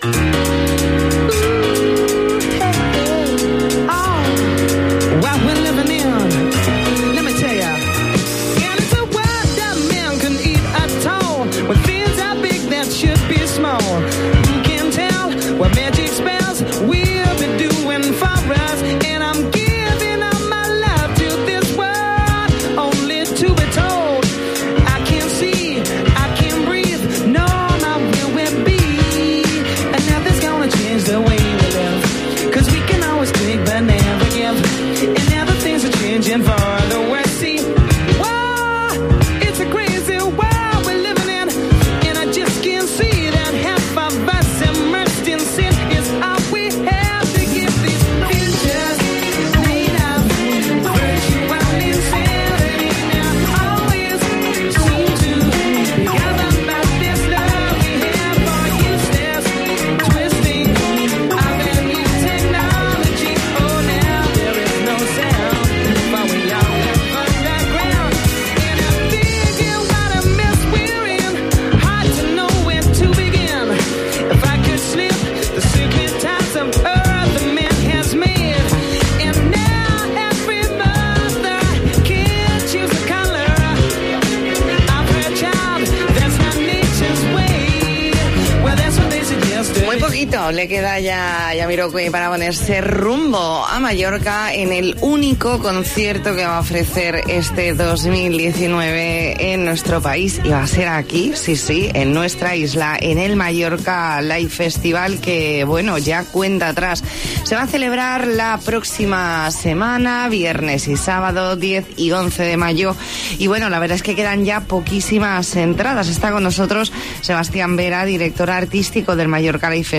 Entrevista en La Mañana en COPE Más Mallorca, jueves 2 de mayo de 2019.